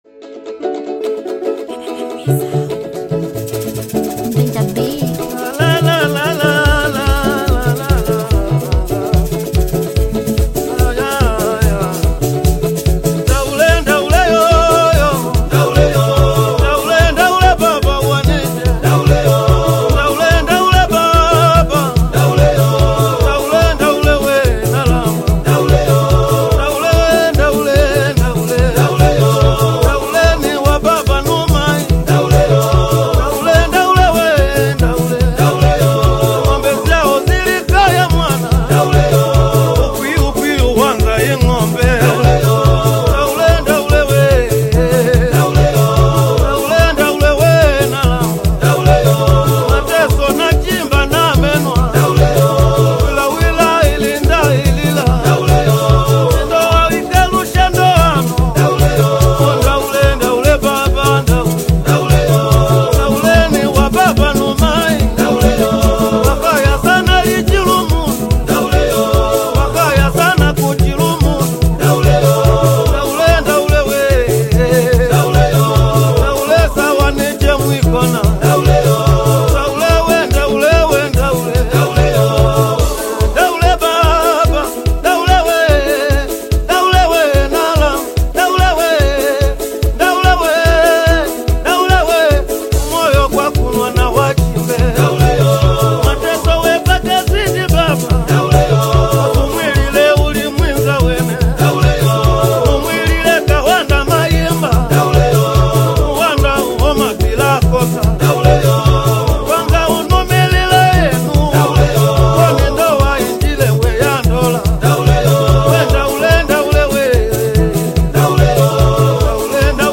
AudioTradition
energetic Afro-Folk/Traditional Tanzanian single